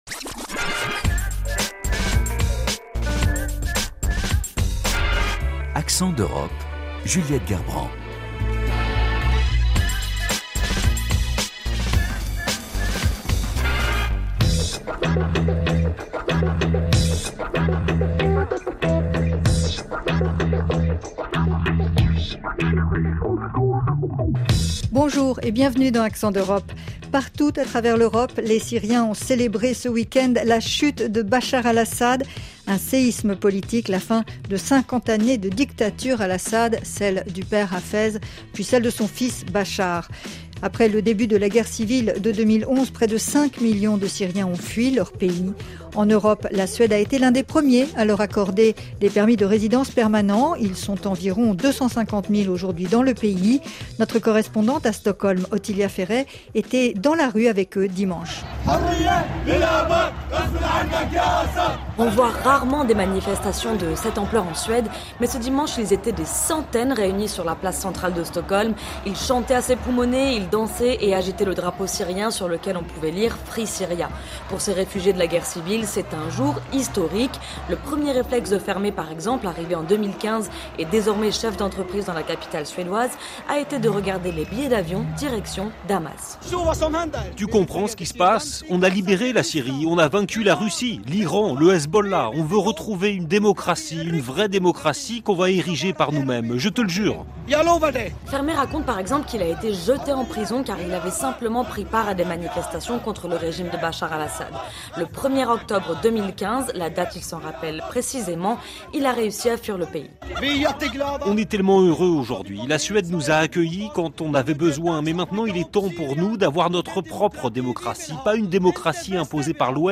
dans les rues de Stockholm.